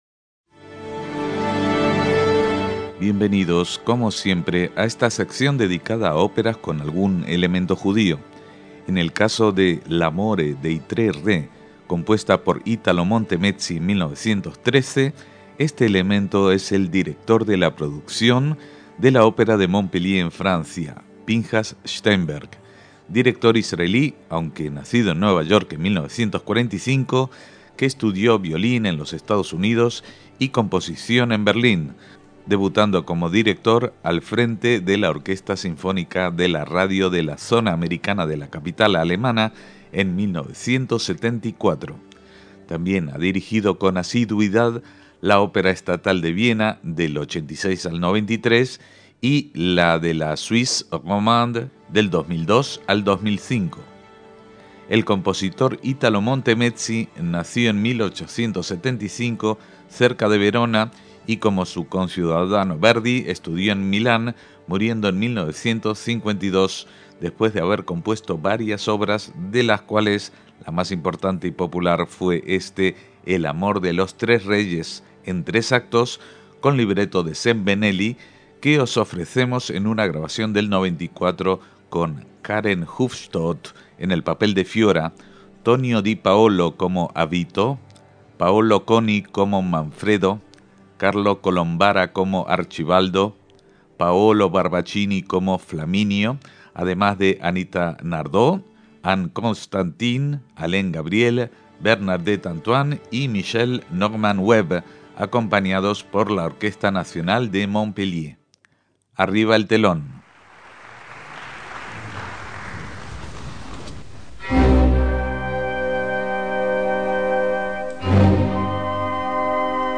una grabación de 1994 de una ópera de un autor poco conocido